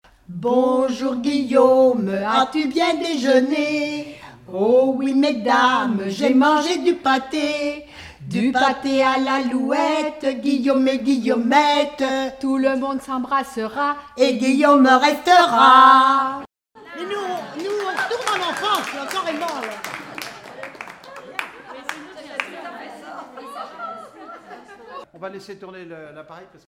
- 007913 Thème : 0079 - L'enfance - Rondes enfantines à baisers ou mariages Résumé : Mon grand Guillaume, as-tu bien déjeuné ?
ronde à embrasser
Pièce musicale inédite